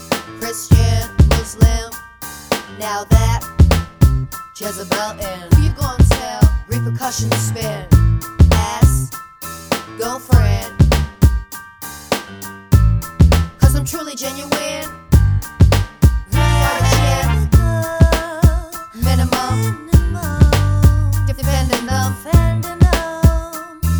no Backing Vocals R'n'B / Hip Hop 4:05 Buy £1.50